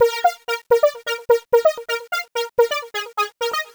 Eurofissure Bb 128.wav